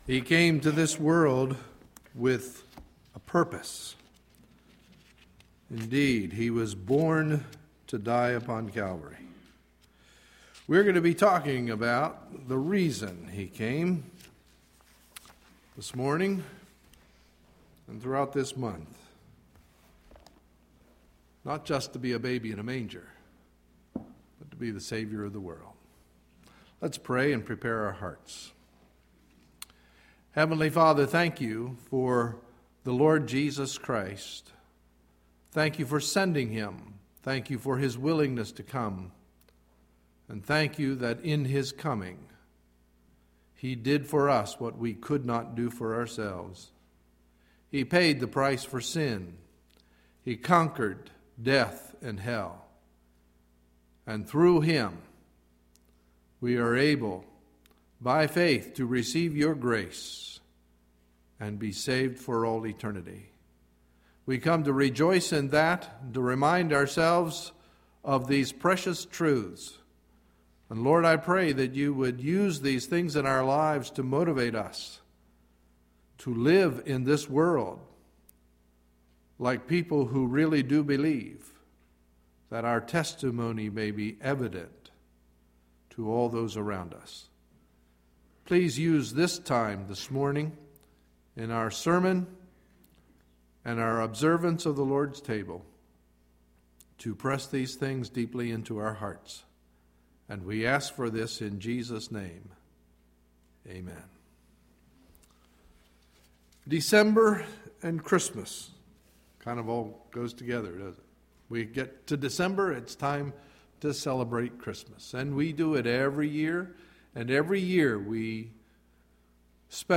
Sunday, December 4, 2011 – Morning Message